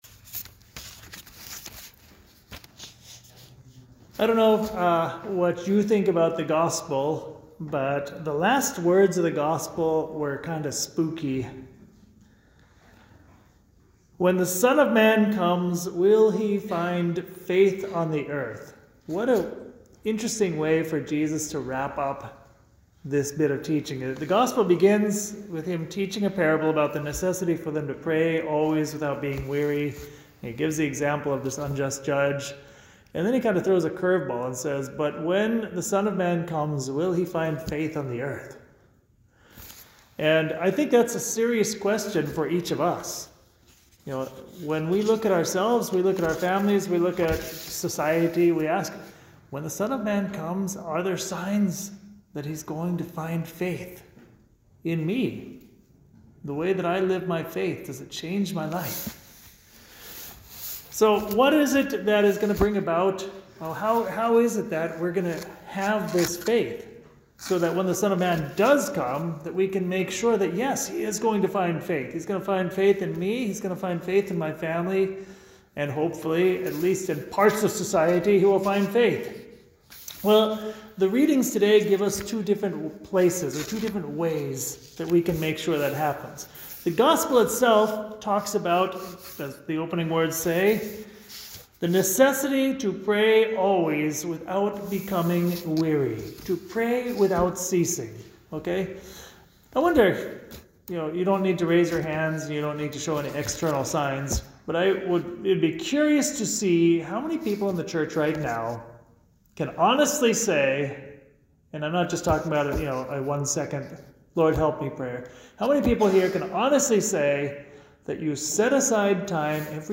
29th Sunday in Ordinary Time Homily
at Saint Patrick Church, Armonk, NY.